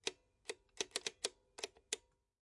BRA1: The Accountant » AddingMachine PAPER rustle 03
描述：A couple of deliciously vintage adding accounting machines. This is the sound of early modern bookkeeping at its finest.
标签： accounting mechanical machinery machine button vintage office foley
声道立体声